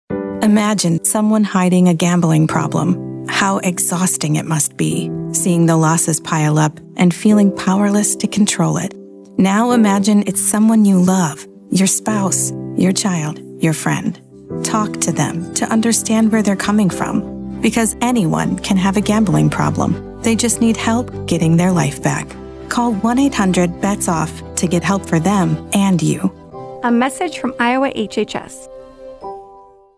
Radio spot :30 Radio Spot | Get Help for Them and You | Option 1 This campaign encourages individuals to reach out when they have questions or concerns about how gambling has impacted them and their loved one.